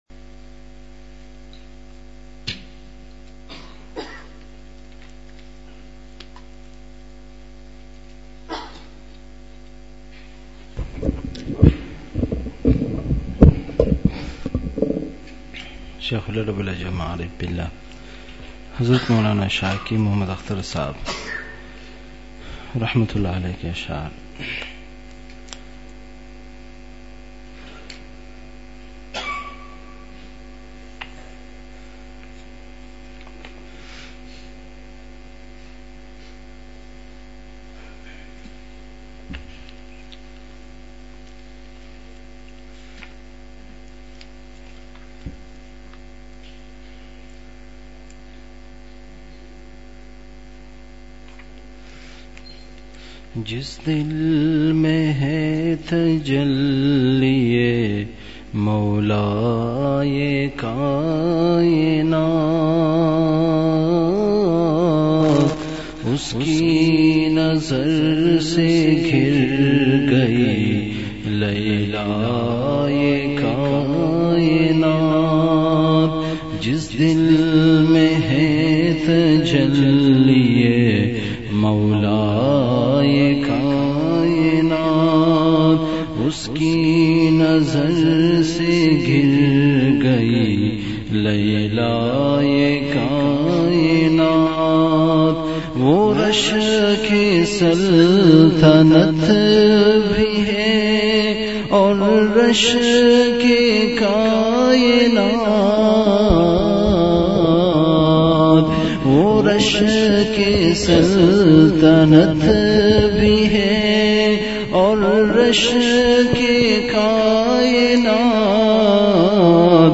مقام:مسجد اختر نزد سندھ بلوچ سوسائٹی گلستانِ جوہر کراچی
بیان کے آغاز میں اشعار کی مجلس ہوئی۔۔